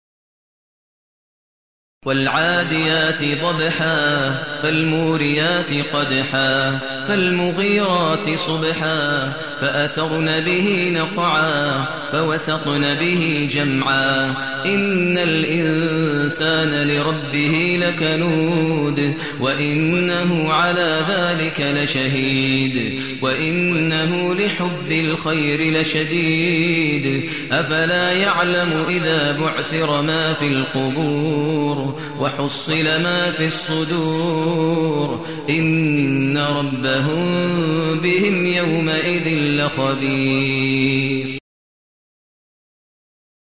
المكان: المسجد النبوي الشيخ: فضيلة الشيخ ماهر المعيقلي فضيلة الشيخ ماهر المعيقلي العاديات